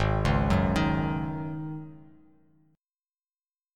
G#7sus4 chord